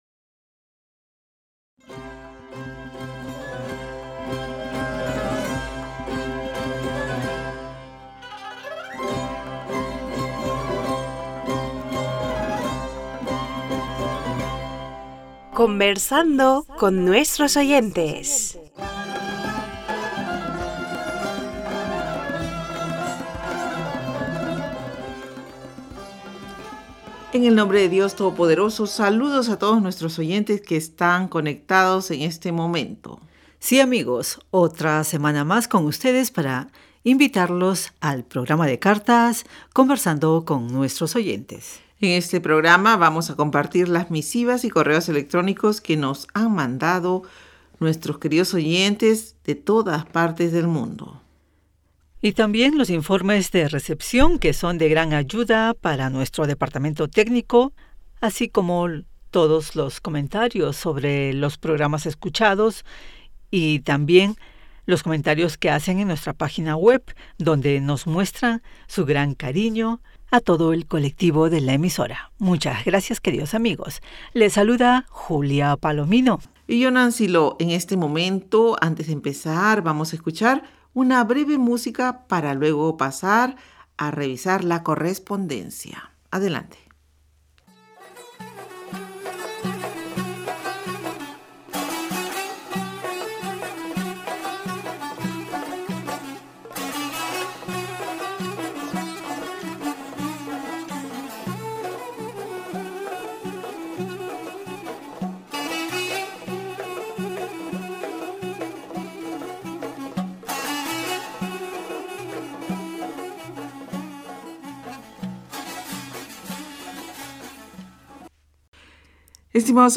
Pars Today-Las entrevistas, leer cartas y correos de los oyentes de la Voz Exterior de la R.I.I. en español.